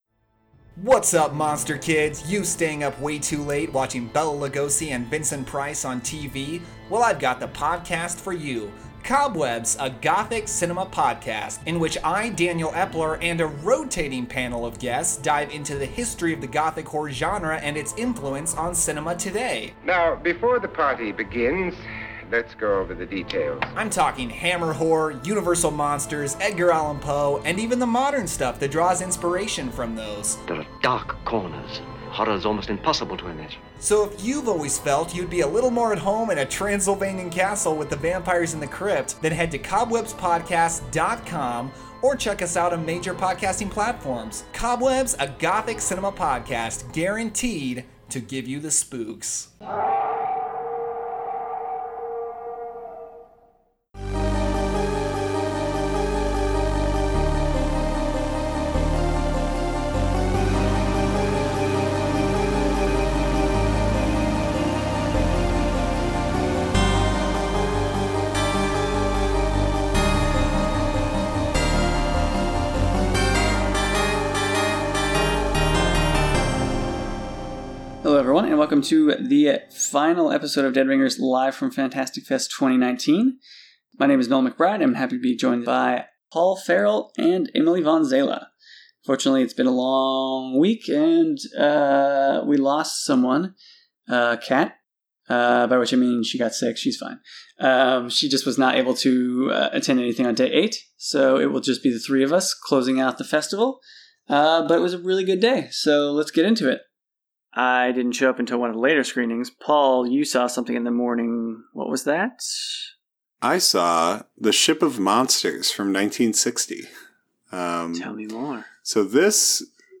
live from this year’s Fantastic Fest to discuss the movies they saw on the eighth and final day of the country’s largest genre movie festival.